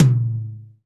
TOM RLTOM0OR.wav